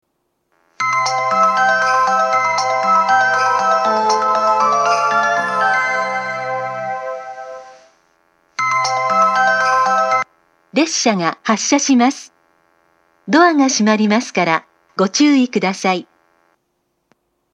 １番線発車メロディー 曲は「小川のせせらぎ」です。